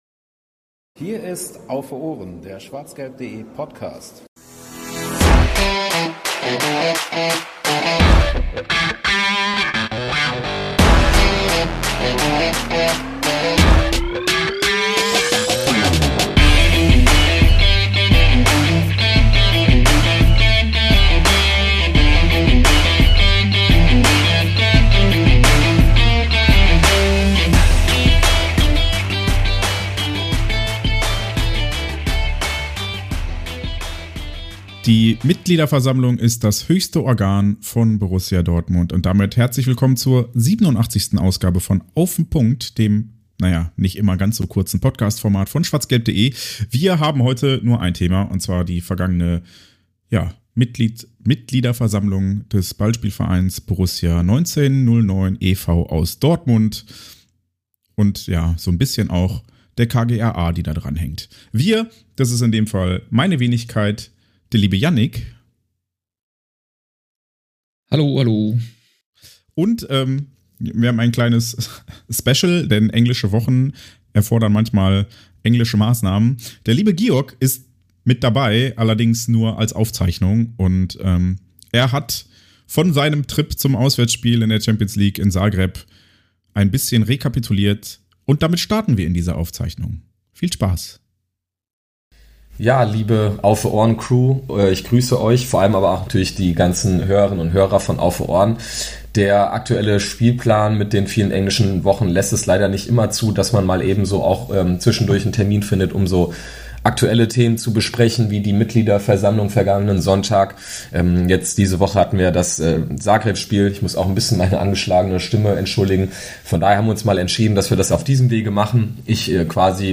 Unser Live-Auftritt im Rahmen des "Tag der Trinkhallen"